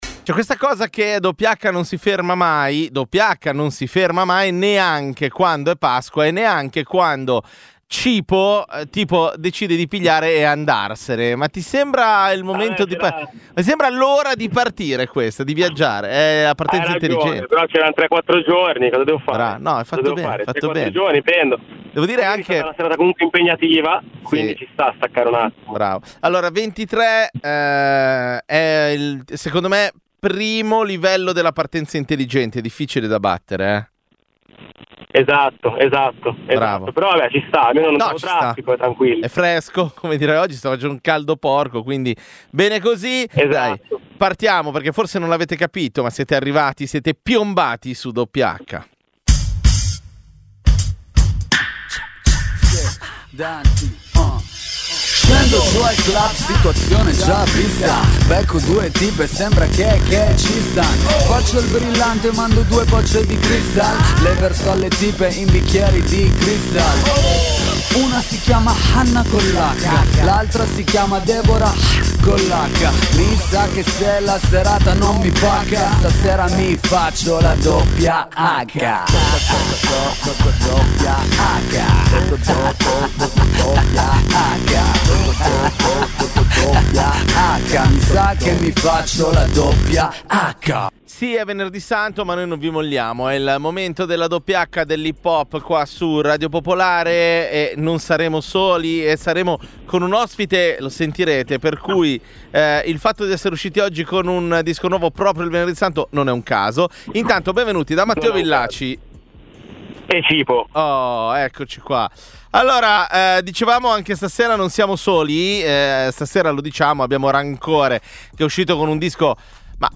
Dal 2011 è la trasmissione dedicata all’hip-hop di Radio Popolare.